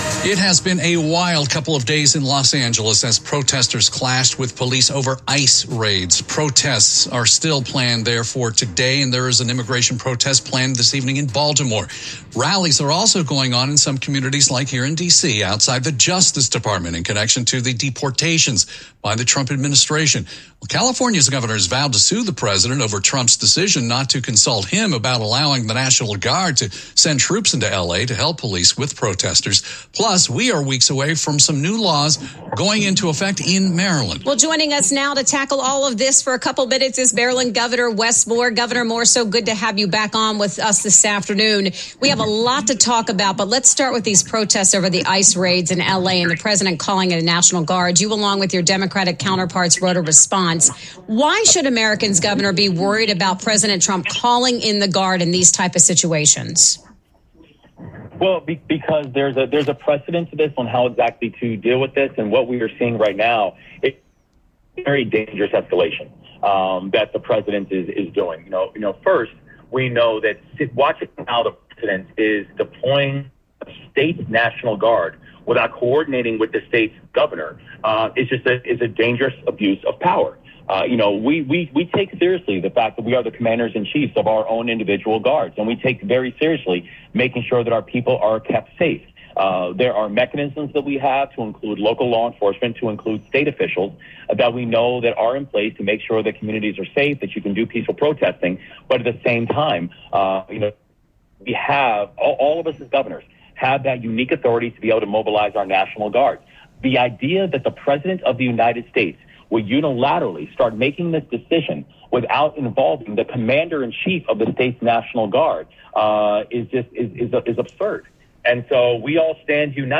Maryland Gov. Wes Moore joined WTOP live on the air Monday afternoon to talk about a wide range of topics in the headlines.
Wes-Moore-Interview.m4a